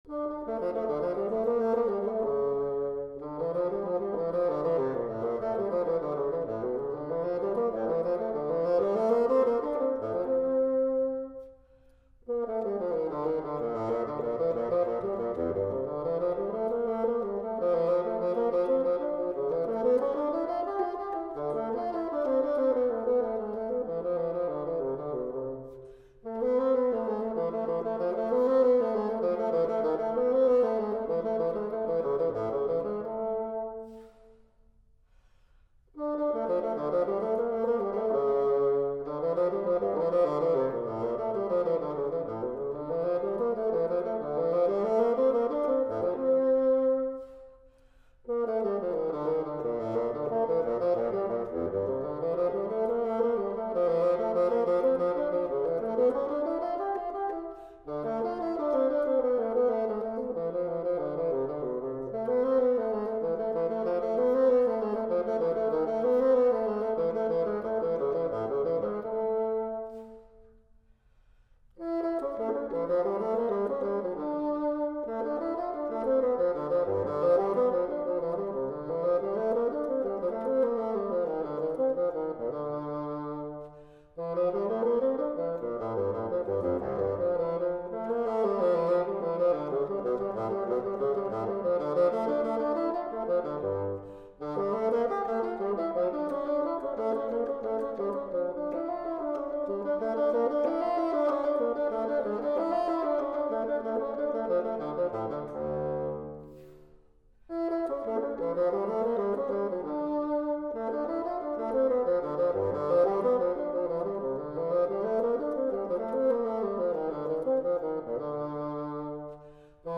vintage performances
Bassoon